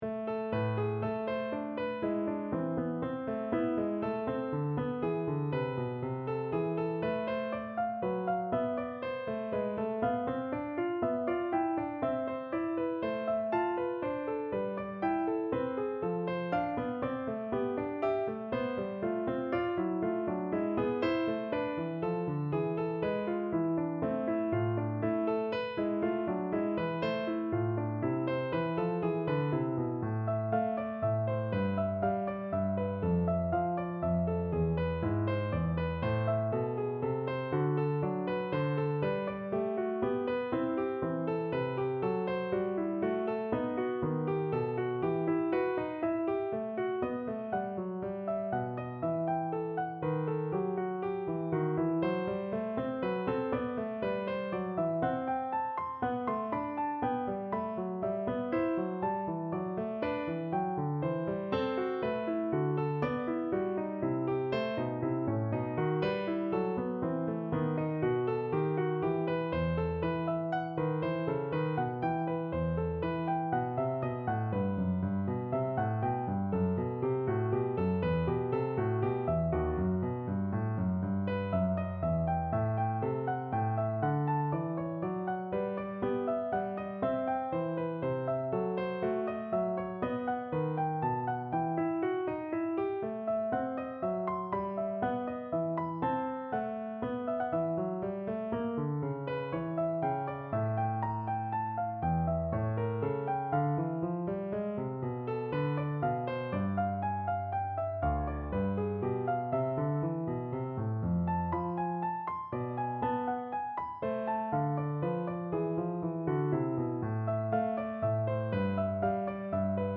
Bach, Johann Sebastian - Partita No.3 in A minor, BWV 827 Free Sheet music for Piano
Classical Piano